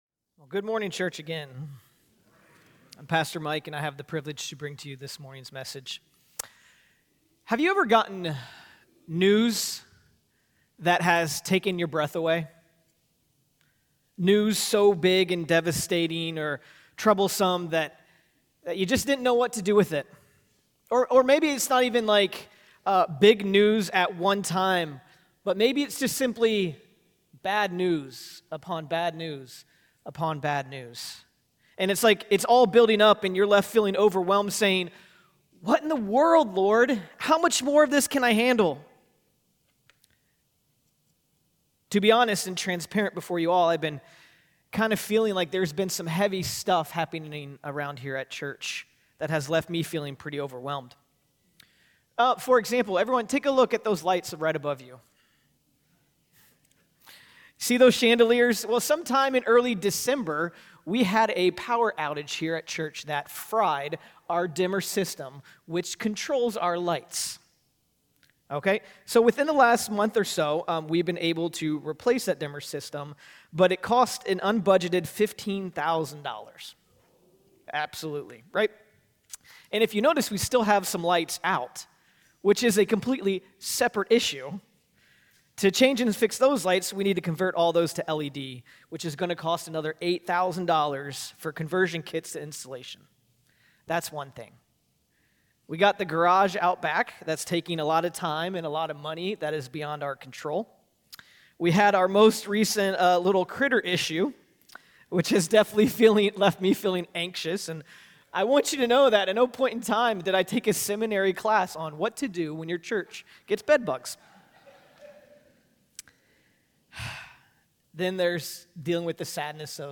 Sermons | First Alliance Church Toledo